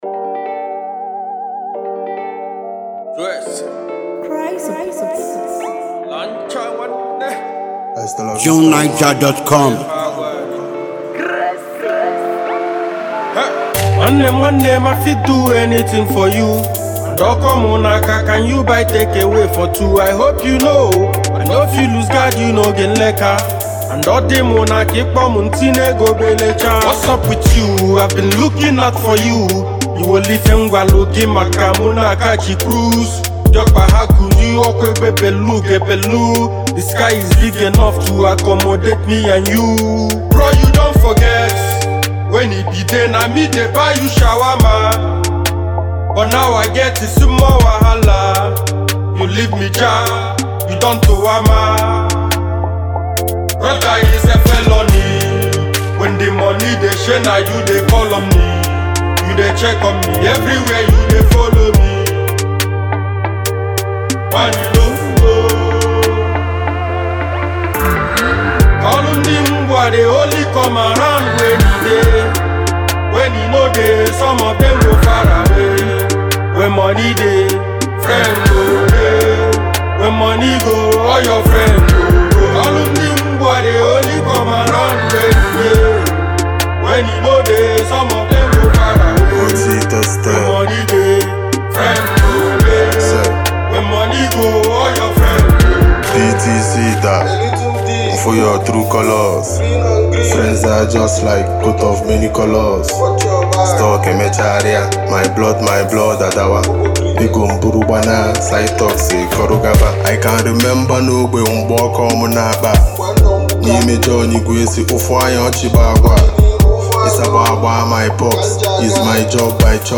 a very talented native Nigerian rapper and musician